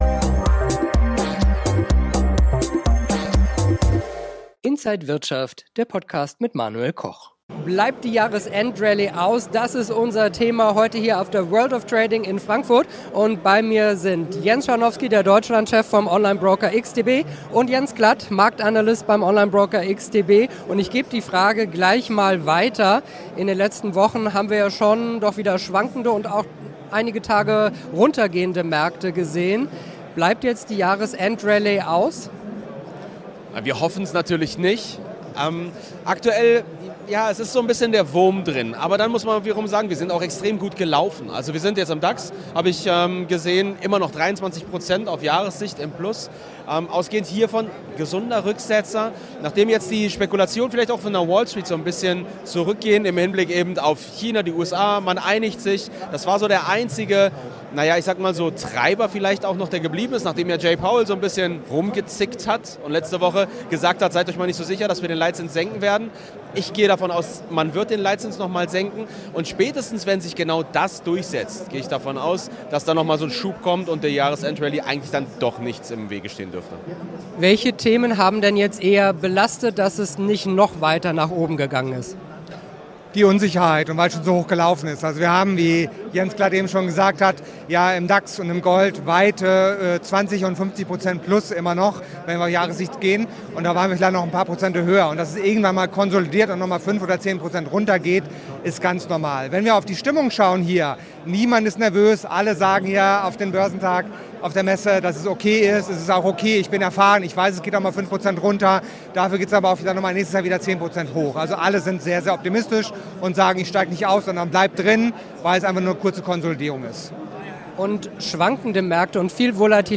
Alle Details im Gespräch mit Inside
auf der World of Trading